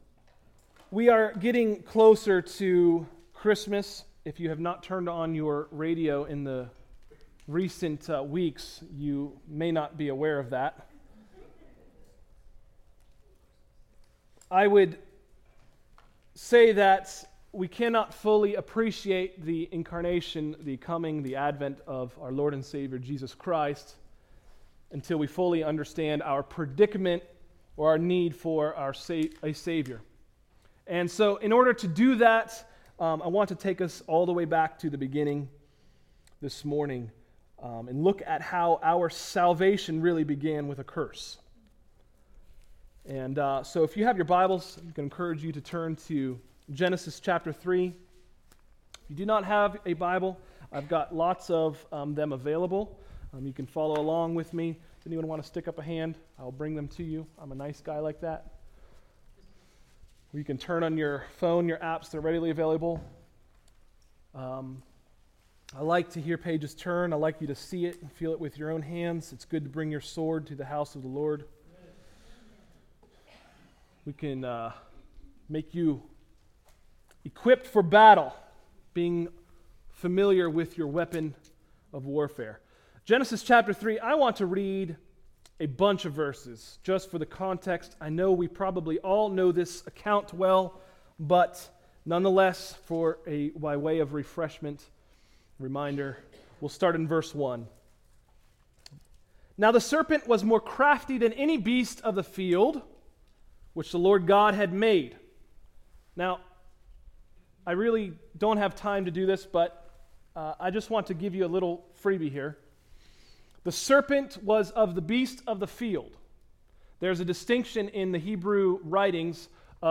Sermons: “The Seed” – Tried Stone Christian Center